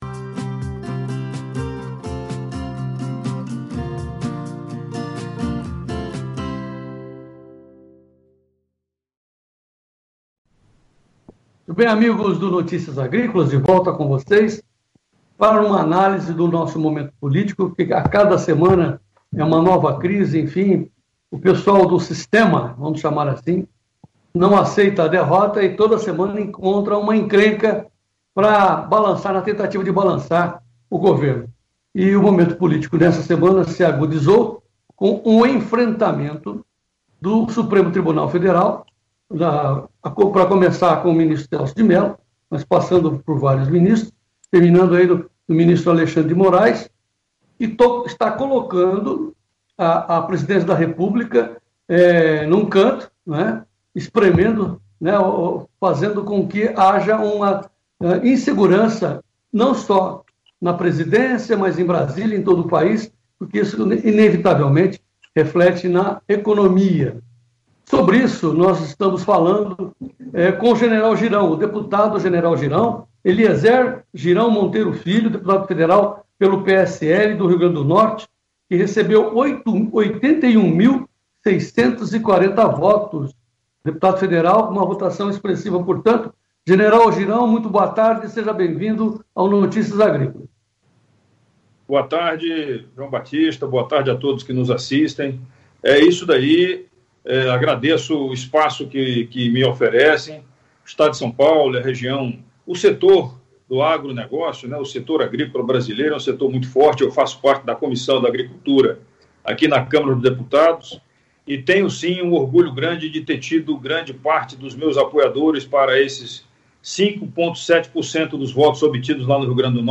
(acompanhe a entrevista na íntegra, acima).